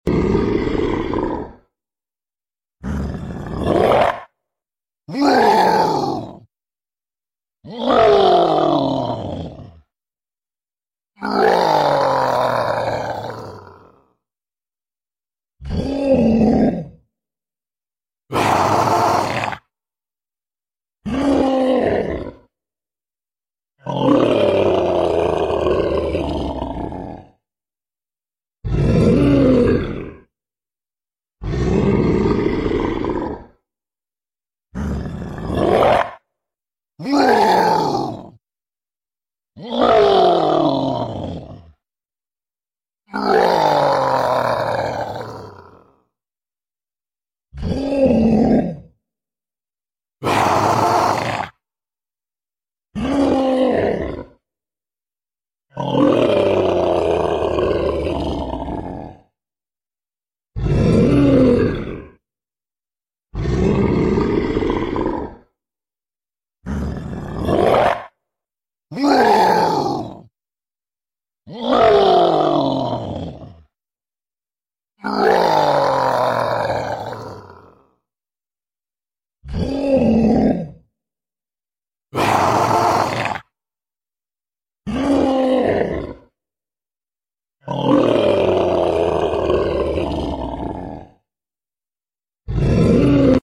Monster roaring sounds Scary monster sound effects free download